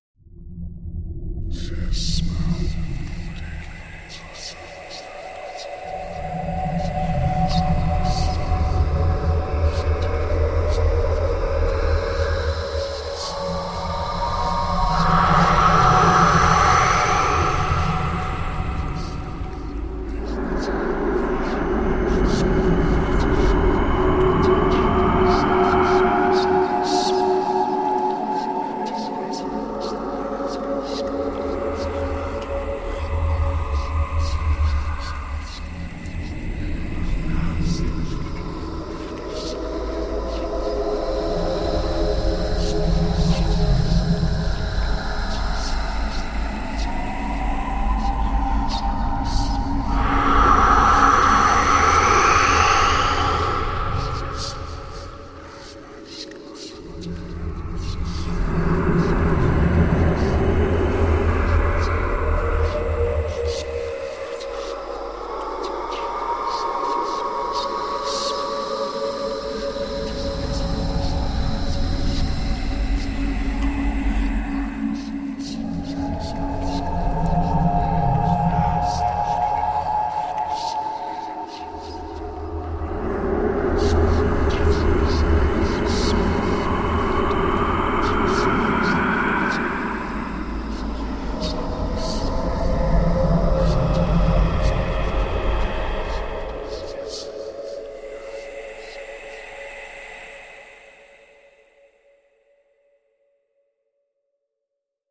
golos-dyavola-iz-peschery
• Категория: Звуки из ада
• Качество: Высокое